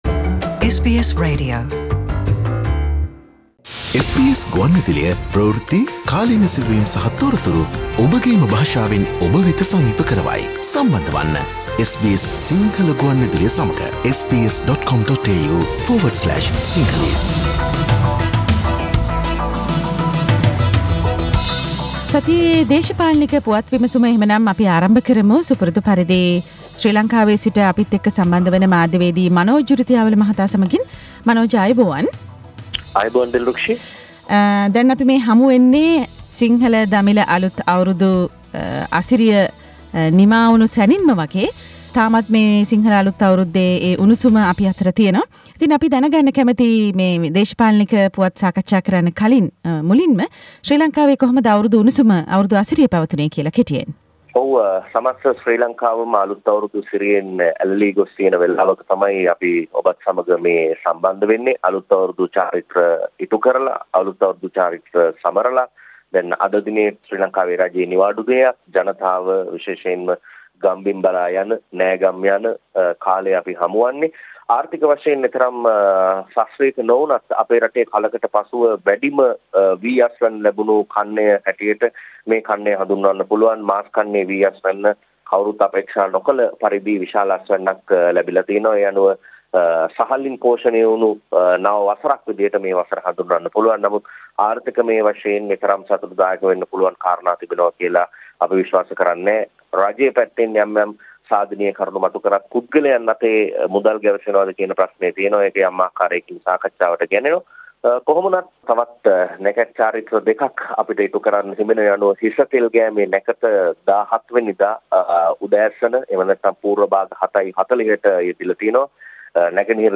සතියේ ශ්‍රී ලංකික දේශපාලන පුවත් විග්‍රහය | SBS Sinhala
සතියේ දේශපාලන පුවත් සමාලෝචනය